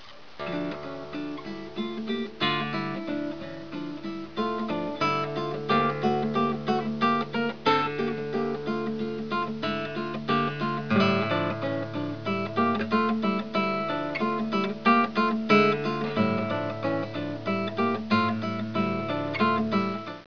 Here are a few pictures of a guitar made with purpleheart backs and sides.� Sitka spruce top.� Really a nice instrument. � Hear Dusky Rose, the purpleheart.